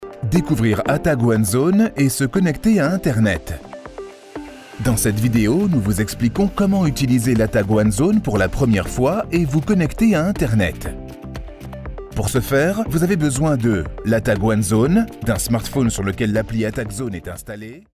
Commercieel, Natuurlijk, Veelzijdig, Vriendelijk, Zakelijk
Explainer